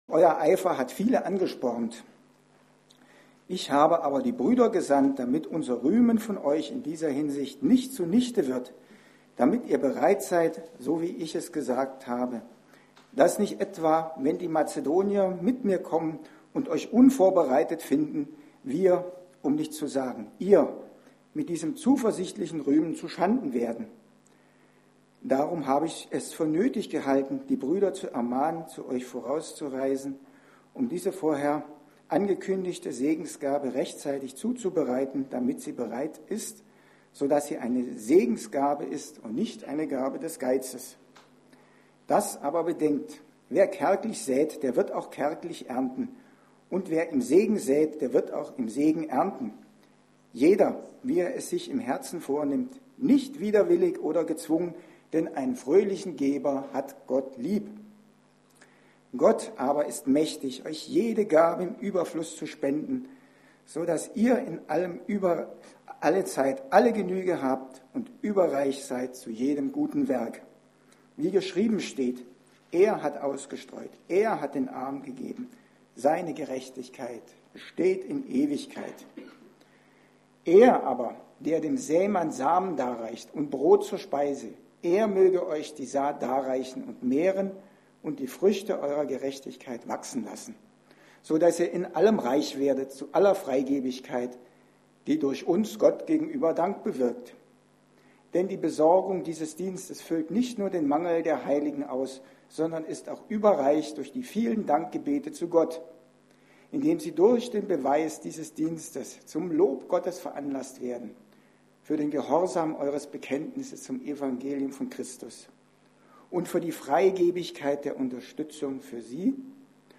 Korinther 9,1-15 Dienstart: Predigt